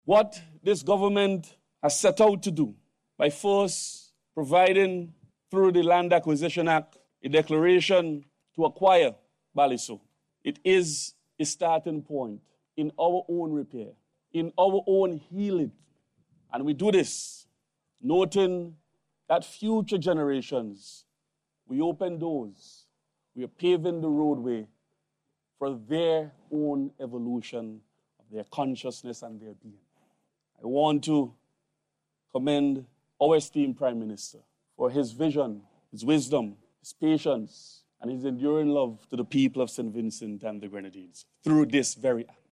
Minister James was delivering remarks at the Wreath Laying Ceremony at the Obelisk in Dorsetshire Hill.